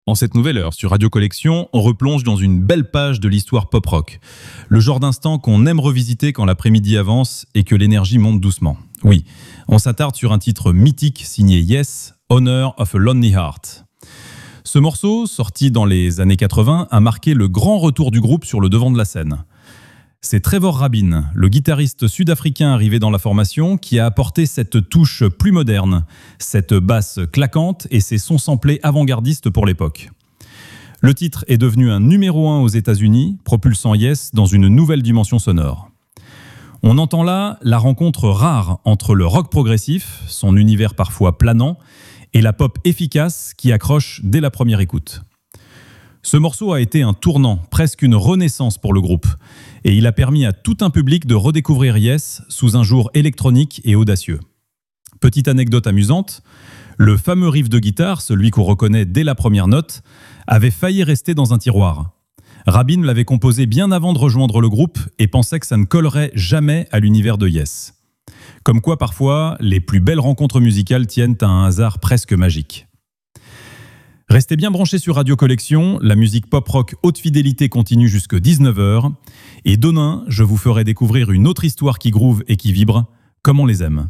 Une chronique vivante qui mêle souvenirs, anecdotes et découvertes pour un véritable voyage quotidien dans l’histoire des artistes préférés des fans de Pop Rock, des années 70 à aujourd’hui.